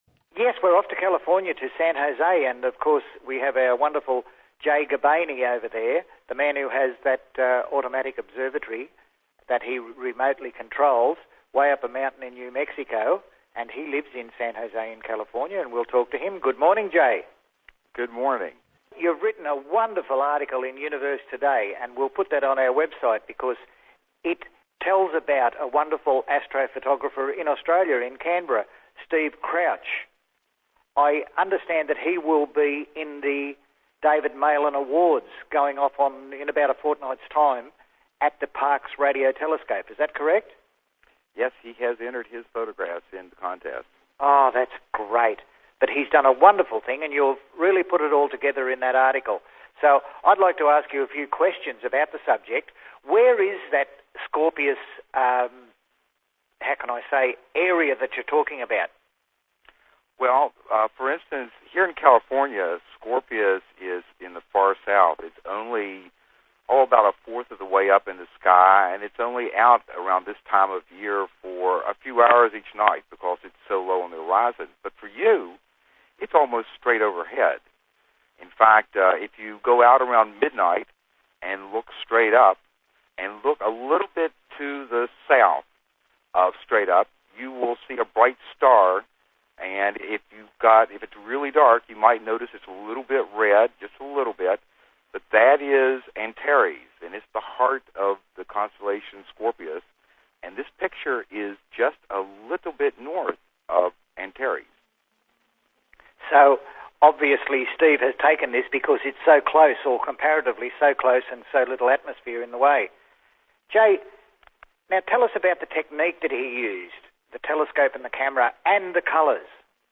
• Radio Inteviews